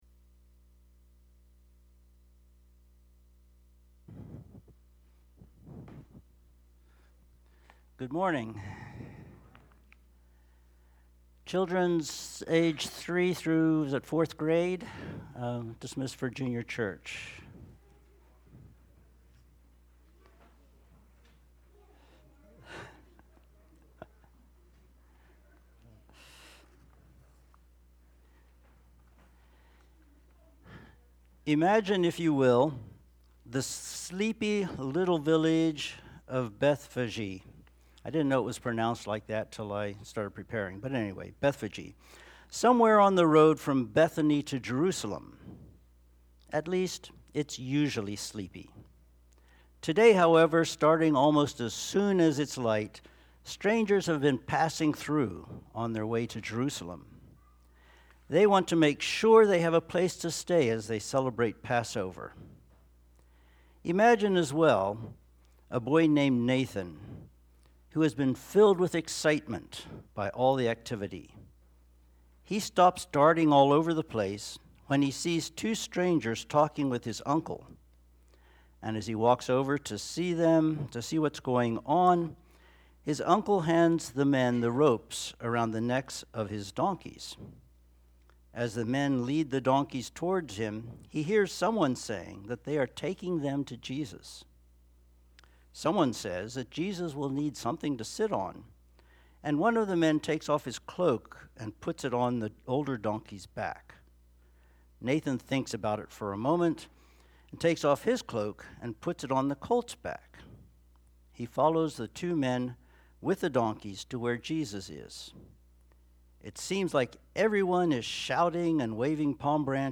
Idaville Church » Sermons